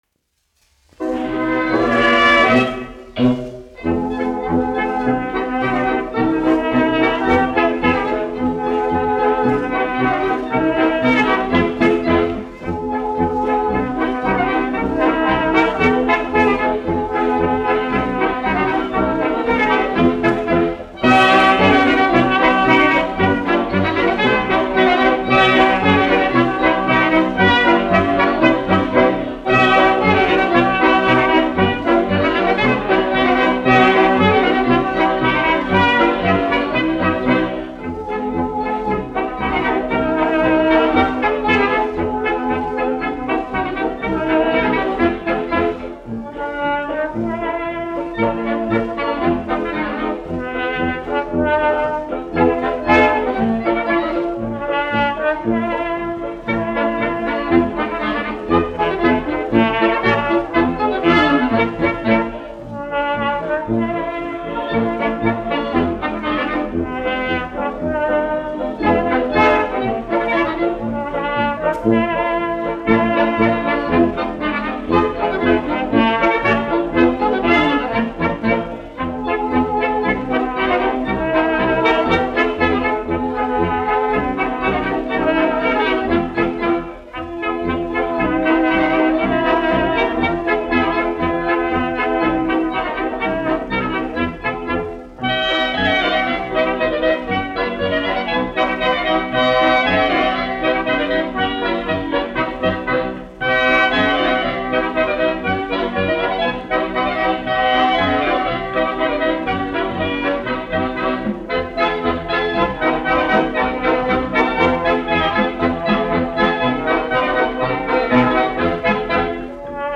1 skpl. : analogs, 78 apgr/min, mono ; 25 cm
Polkas
Tautas deju mūzika -- Latvija
Skaņuplate